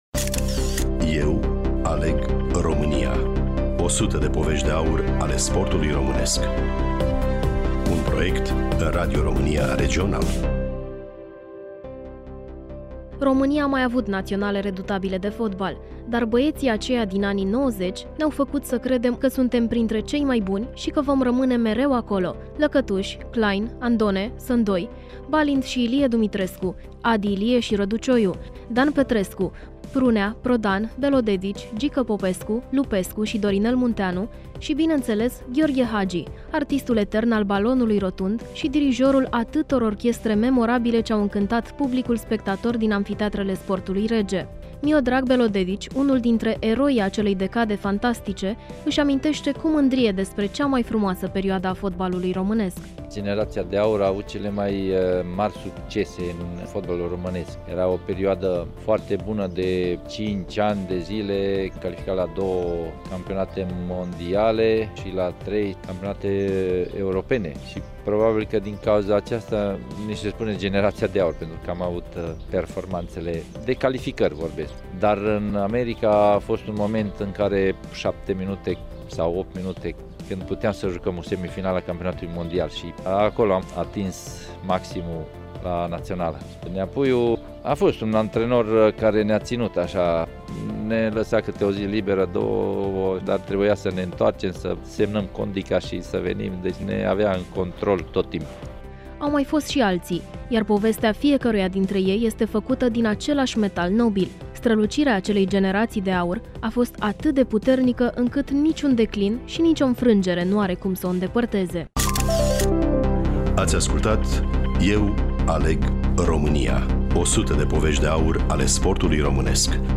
Studioul: Bucuresti FM